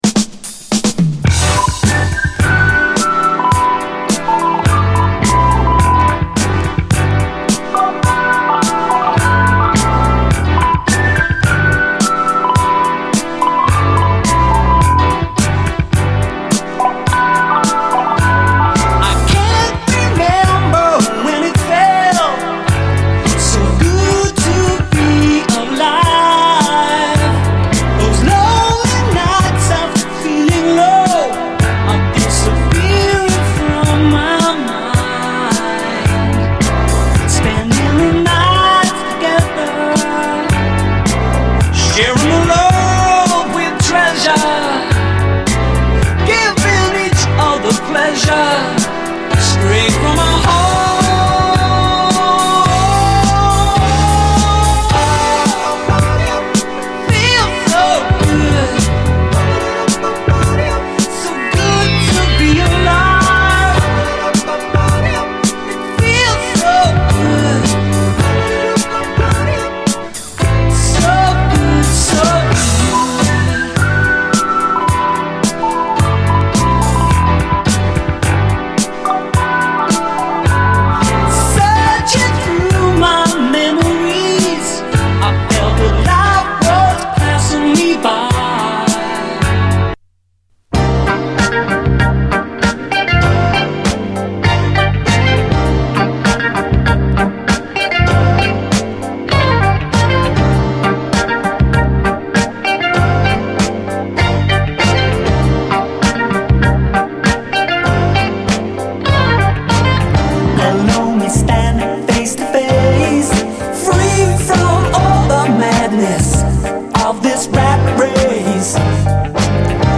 SOUL, 70's～ SOUL, DISCO
UK産ブルーアイド・ソウル傑作！
（試聴ファイルは以前の録音のもの。今回の盤はチリノイズ一切なく非常に綺麗に聴けます。）